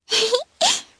Hanus-Vox_Happy1_jp.wav